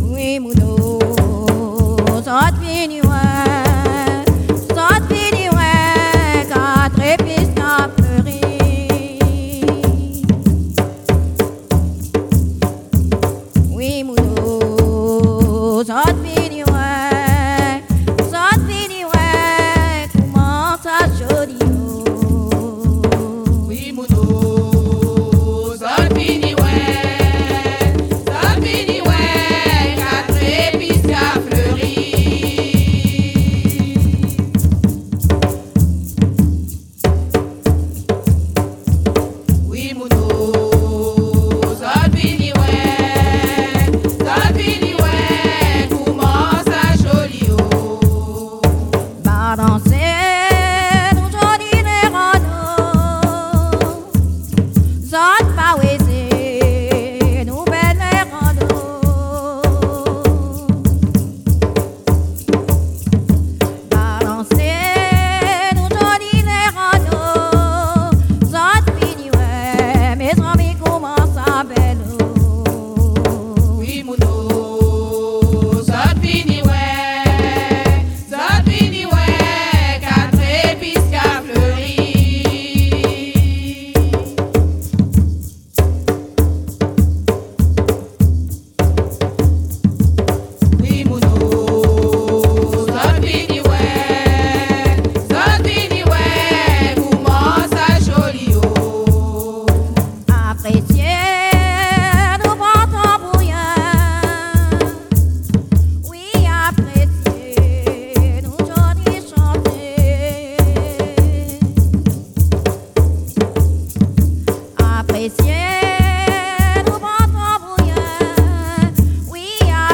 Saint-Georges-de-l'Oyapoc
danse : léròl (créole)
Pièce musicale inédite